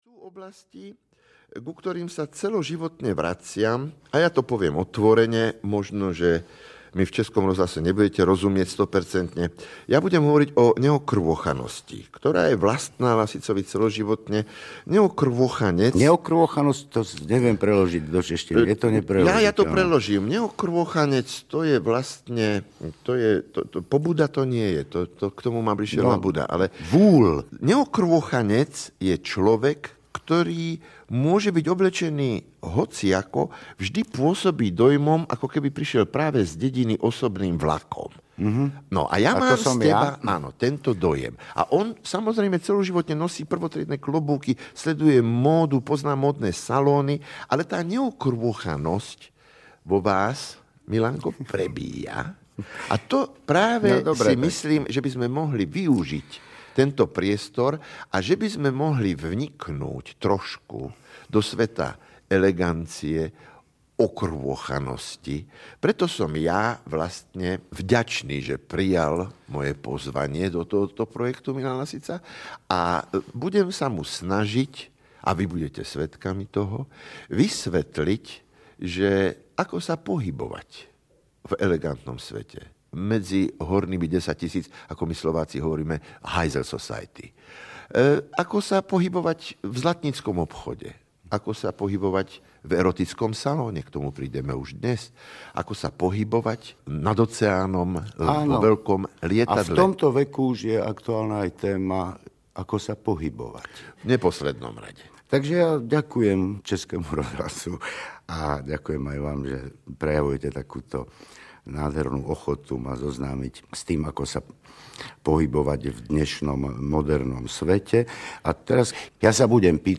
Ukázka z knihy
Točilo se přímo z jedné vody na čisto. Dílů bylo celkem šestatřicet, na tomto kompletu je jich 11, každý výstup je proložen písní, která s vyprávěním souvisí, na řadě z nich zpívá Milan Lasica.
Obě tyto role se pravidelně střídají a snad jenom rozdíl temperamentů občas prozradí, že Satinský je rodem vypravěč a Lasica spíš lakonický komentátor.
• InterpretMilan Lasica, Július Satinský